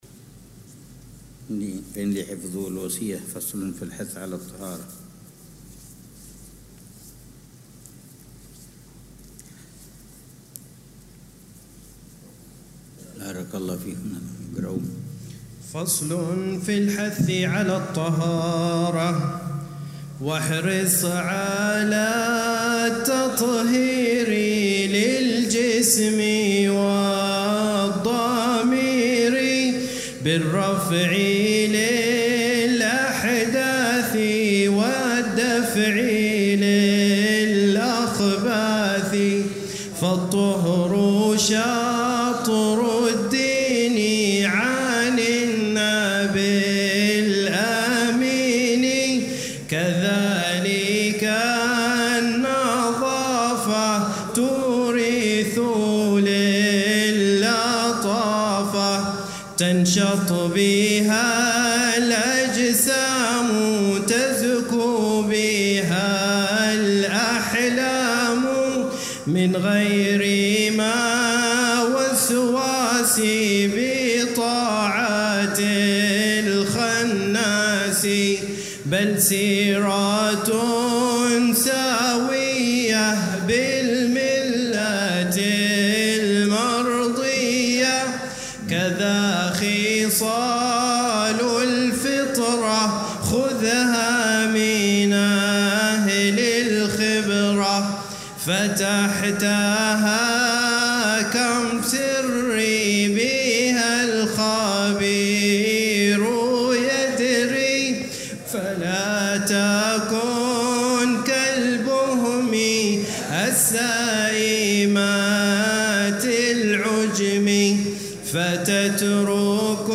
الدرس السابع عشر ( 4 صفر 1447هـ)